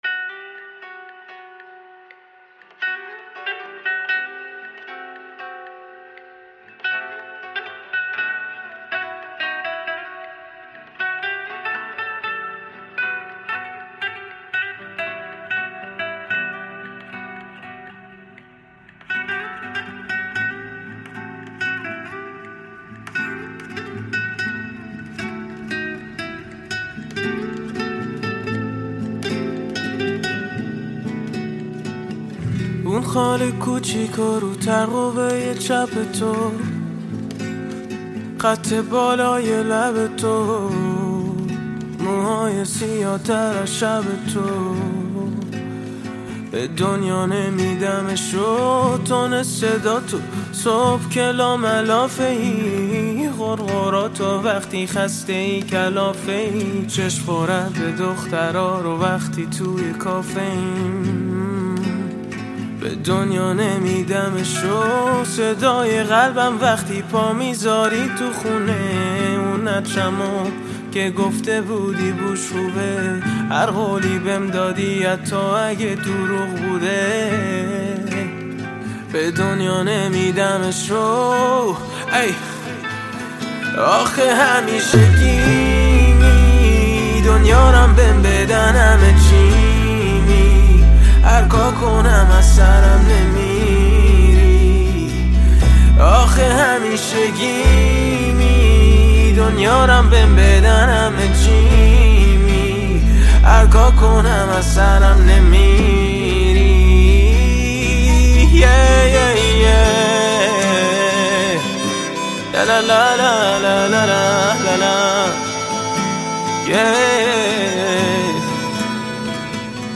تک آهنگ ها
غمگین